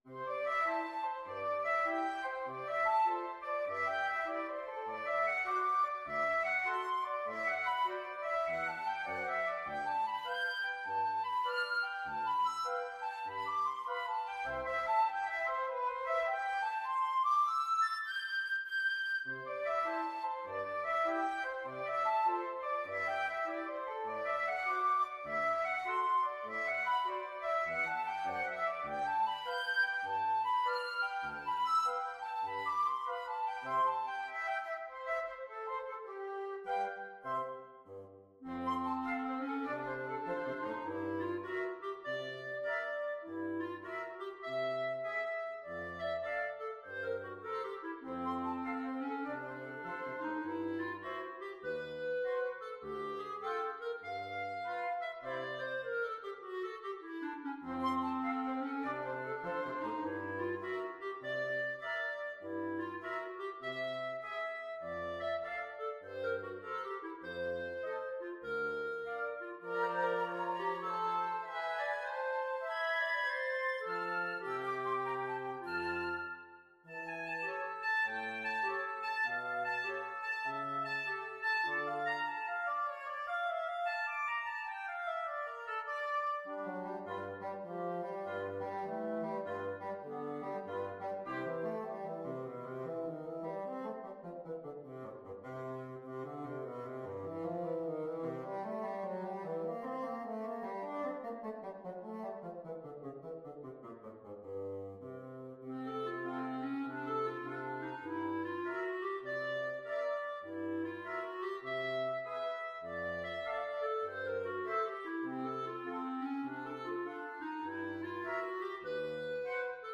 FluteOboeClarinetBassoon
4/4 (View more 4/4 Music)
Lightly = c. 100
Wind Quartet  (View more Advanced Wind Quartet Music)
Jazz (View more Jazz Wind Quartet Music)
Rock and pop (View more Rock and pop Wind Quartet Music)